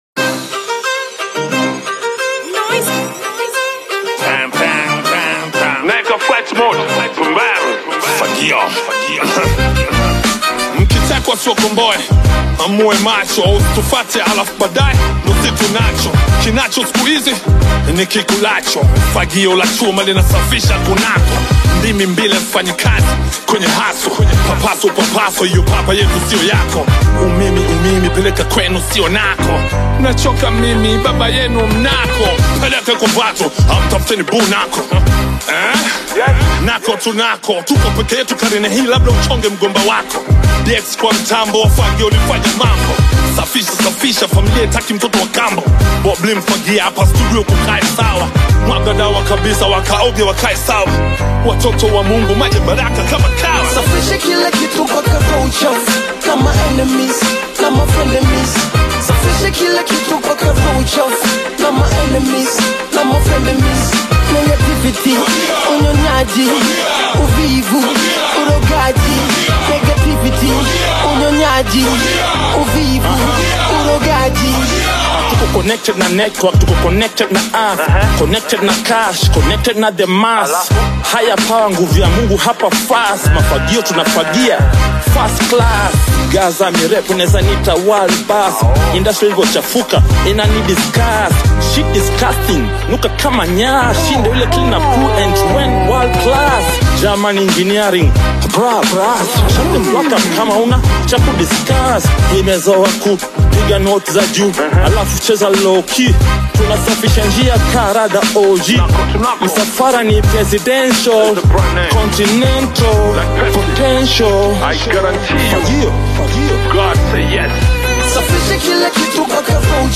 Tanzanian Hip-Hop single
blending raw lyricism and dynamic street-inspired flows.